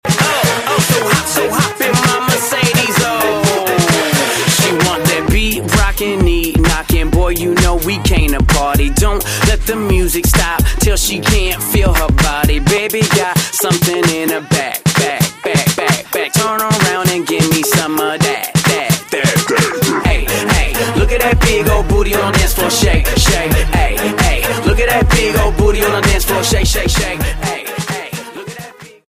D'n'B & Jungle